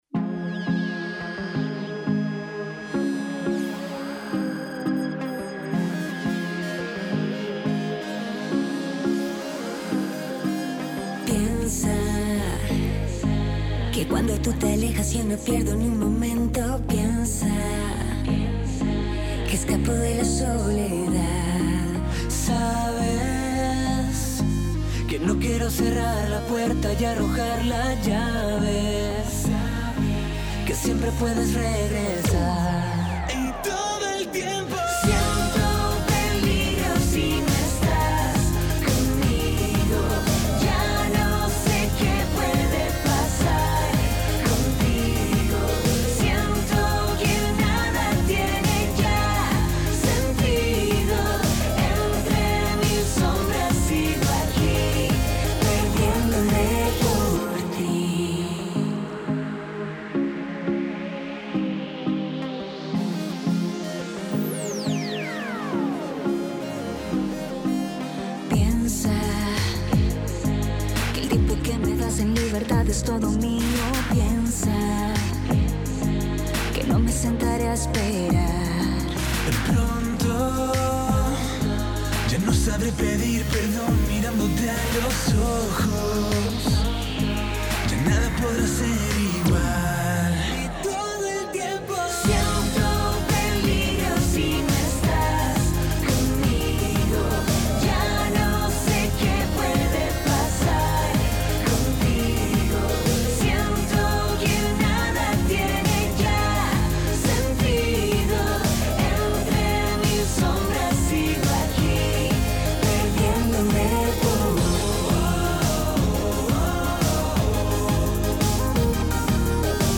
banda de pop nacional